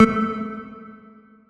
key-press-1.wav